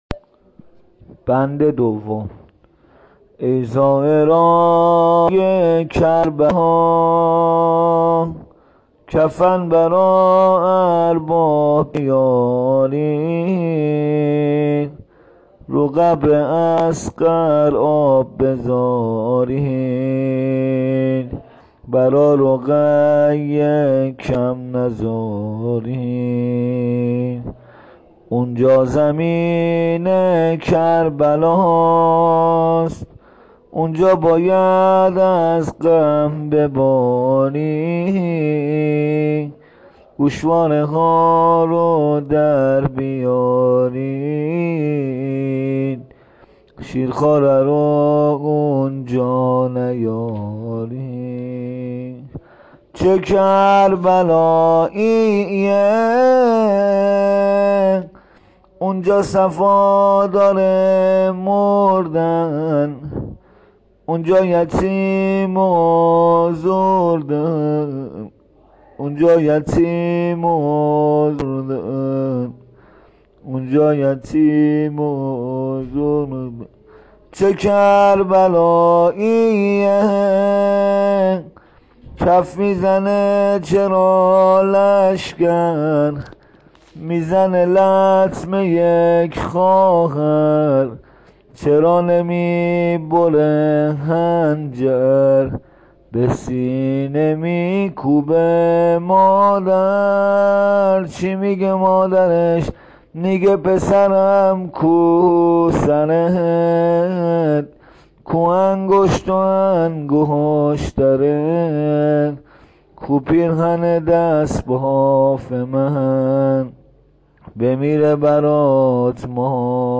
زمینه ی سینه زنی شب سوم محرم